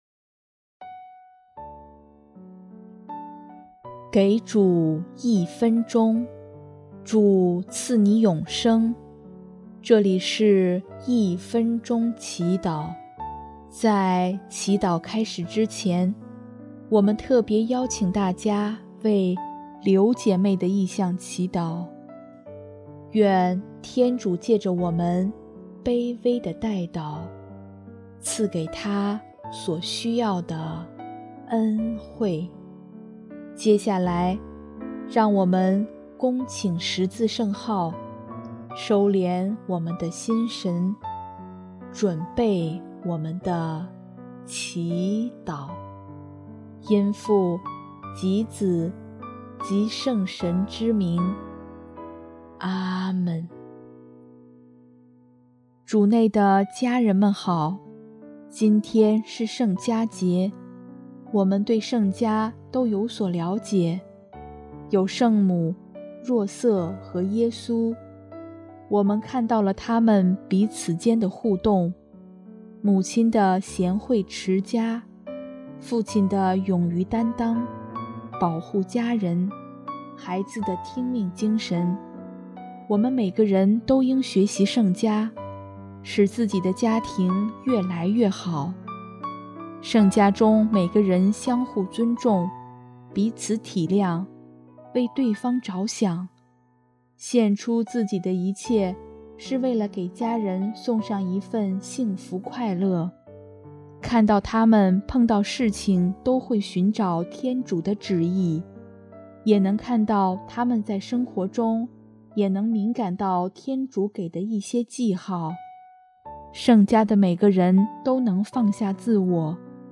【一分钟祈祷】|12月28日 让圣家的爱也住在我们家中